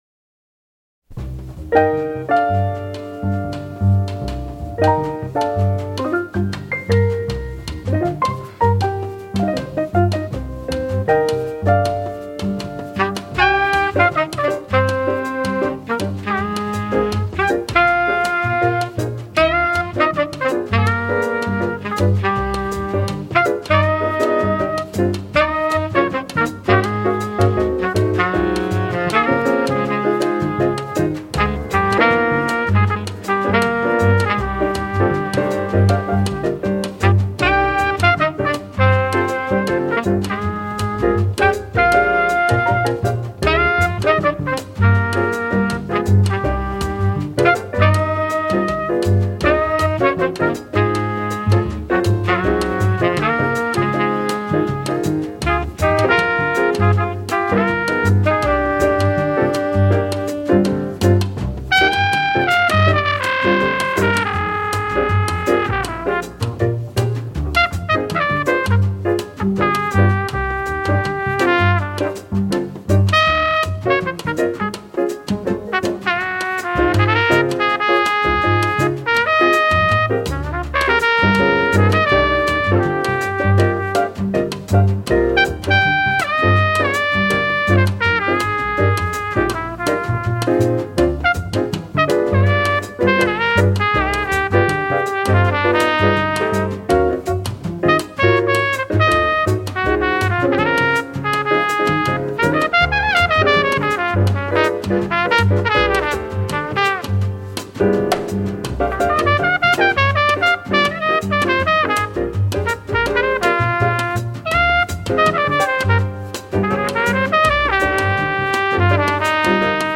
Track1_Jazz_Instrumental.mp3